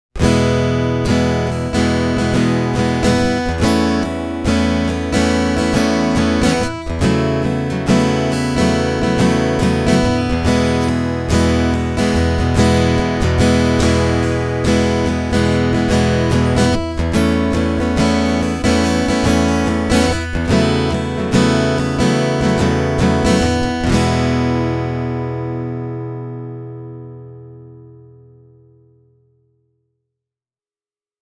Soitossa on aimo annos helisevää diskanttia ja lämmintä bassoa, mutta bassot eivät kumise tai dominoi tämän mallin äänimaailmaa.
Piezoversio on suorasukkaisempi, muttei missään nimessä huono: